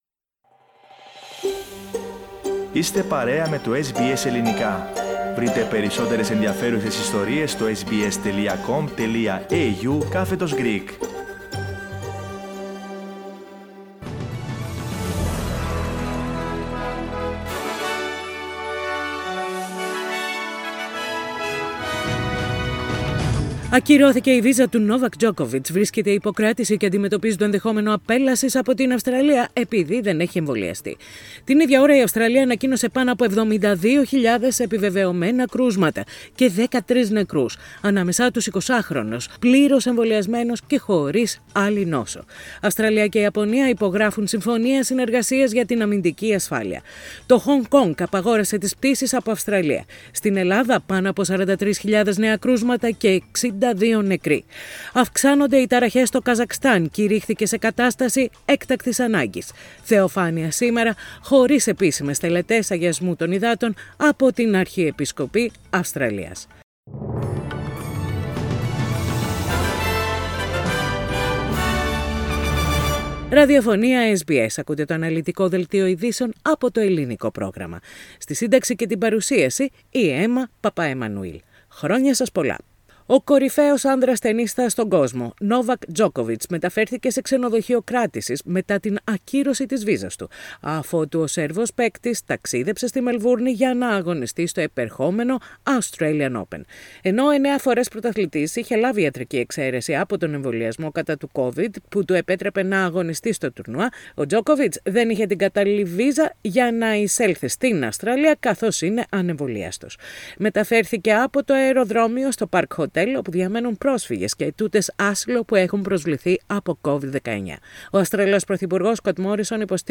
The detailed bulletin of the day, with the main news from Australia, Greece, Cyprus and the international arena.
News in Greek.